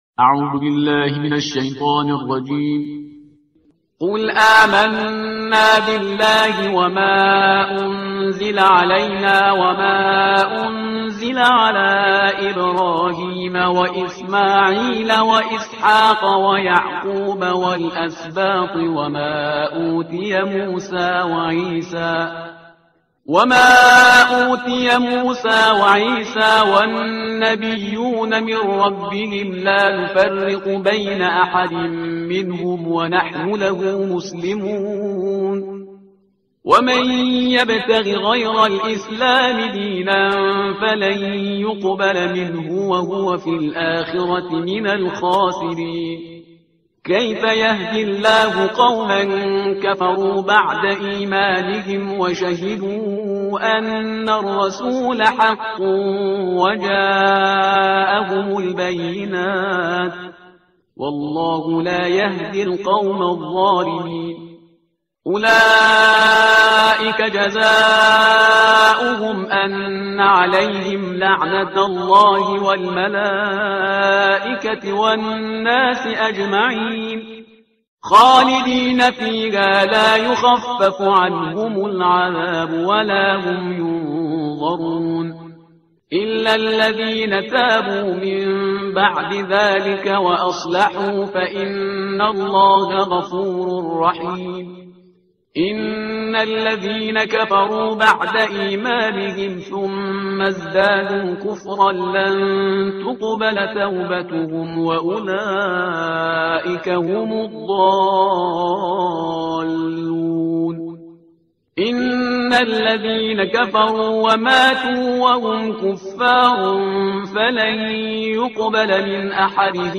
ترتیل صفحه 61 قرآن با صدای شهریار پرهیزگار
Parhizgar-Shahriar-Juz-03-Page-061.mp3